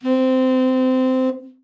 Index of /90_sSampleCDs/Giga Samples Collection/Sax/TEN SAX SOFT